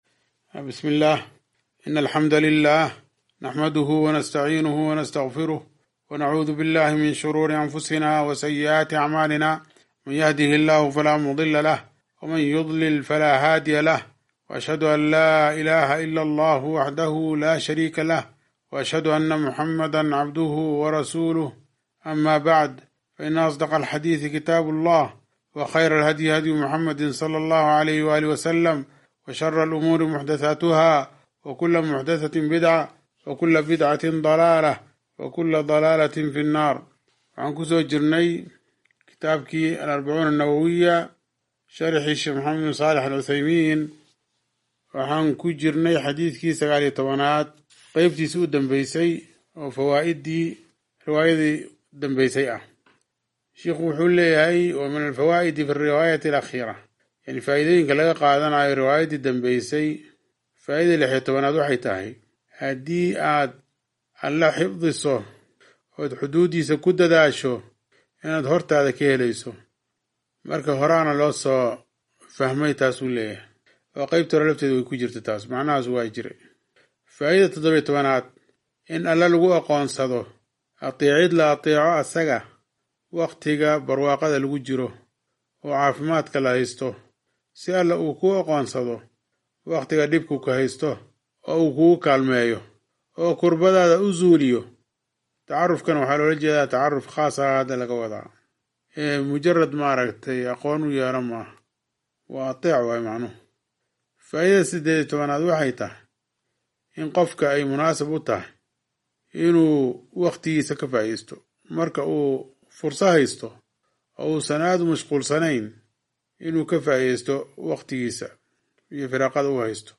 Sharaxa Al-Arbaciin An-Nawawiyyah - Darsiga 38aad - Manhaj Online |